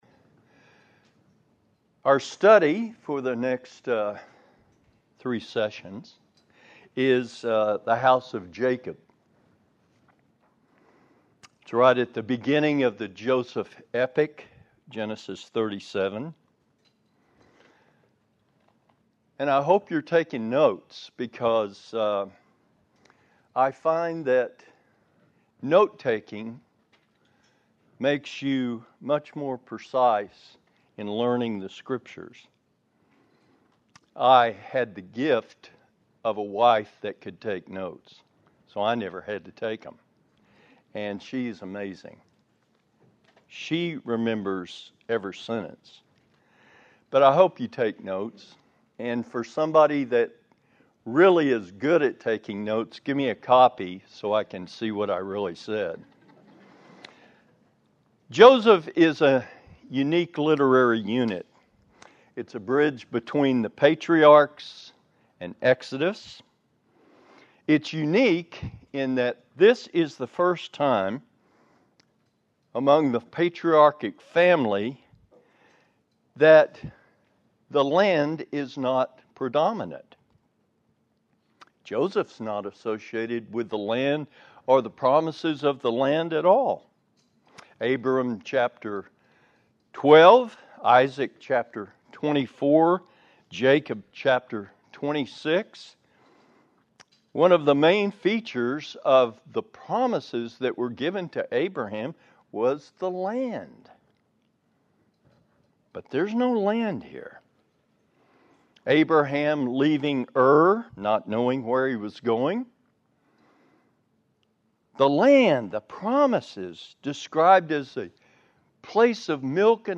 Family Camp 2025 – Lesson 2 (Lesson 1 not recorded)